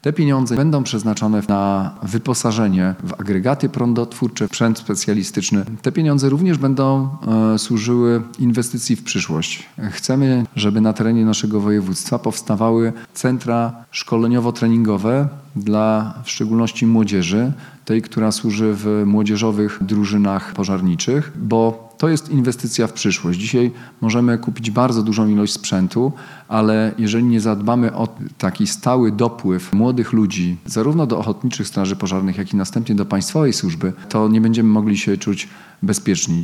Szczegóły wyjaśnia marszałek województwa Olgierd Geblewicz: